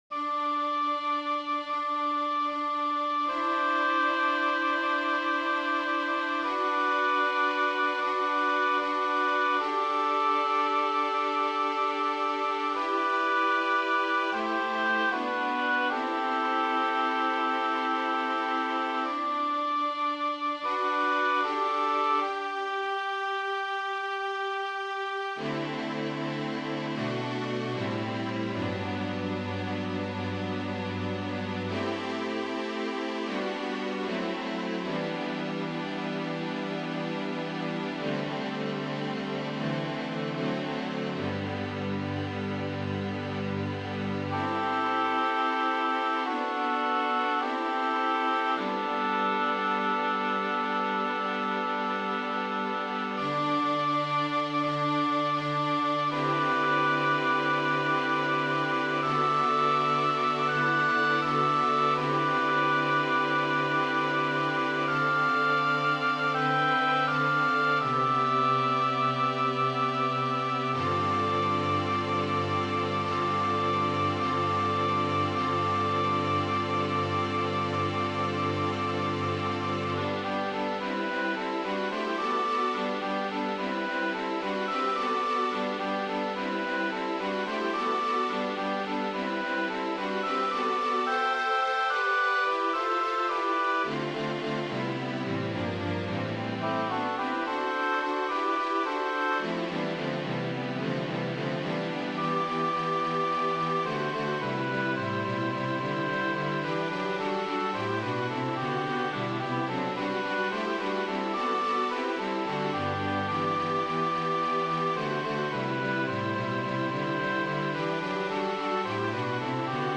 SATB div. Percus. corporal Alta 6 min.